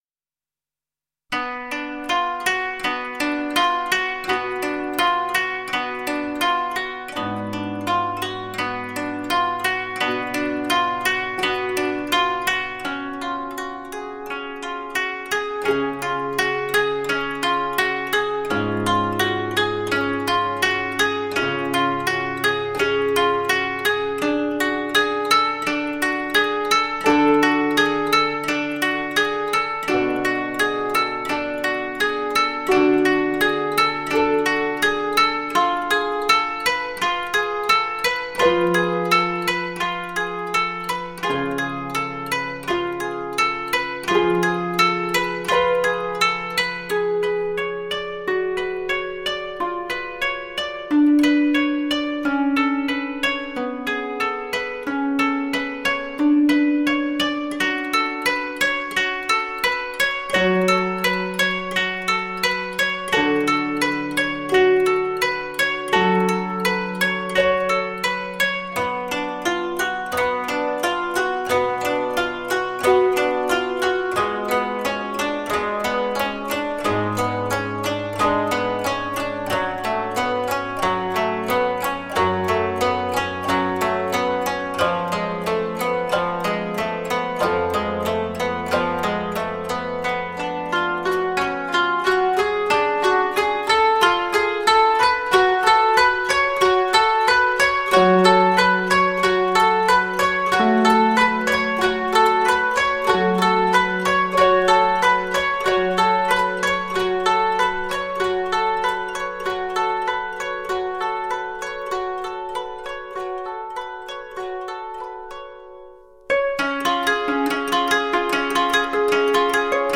協奏曲—
十八絃ソロ
(箏群)
十五絃